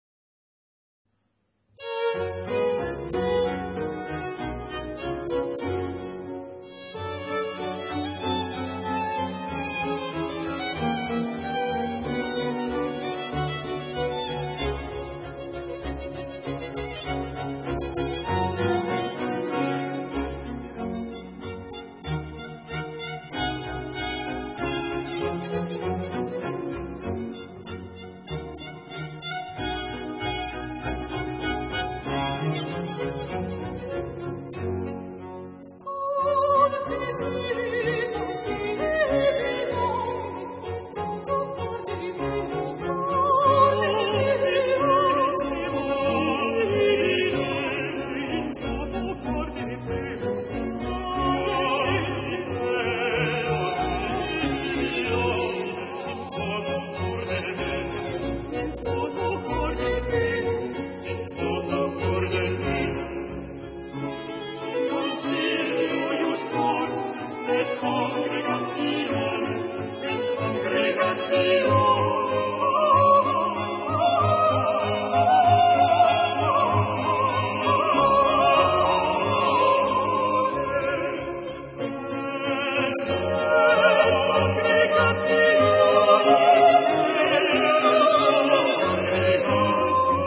La voix est complètement mise en scène.
alto
soprano
basse